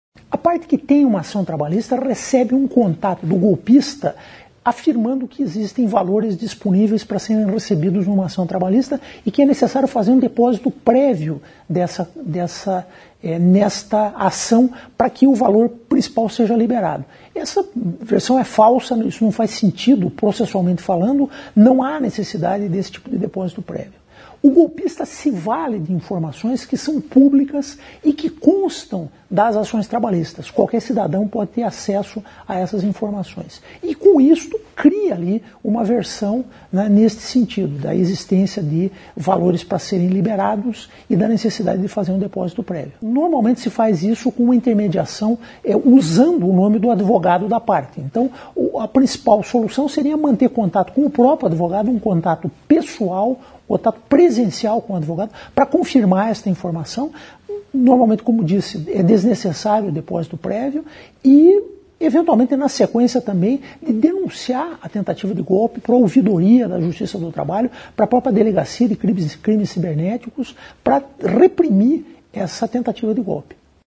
Ouça o que diz o desembargador Célio Waldraff, presidente do TRT-PR.